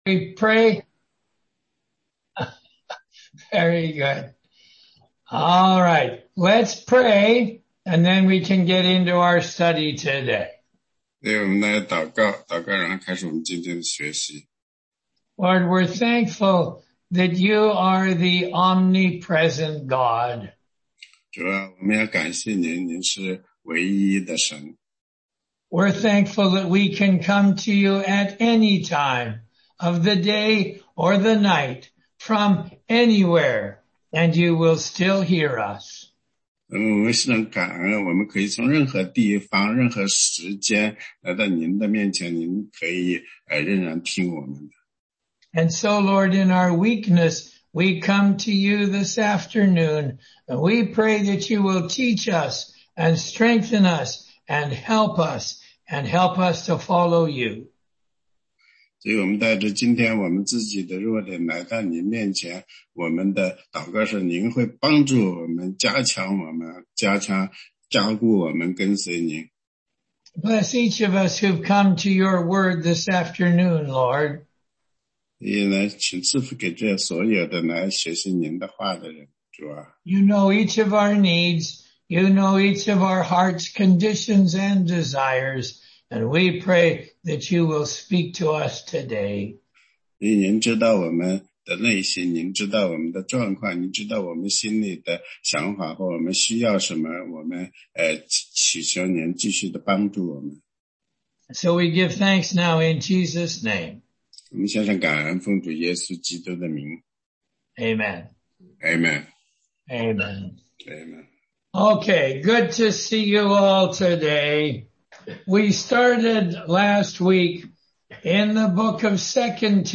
16街讲道录音 - 答疑课程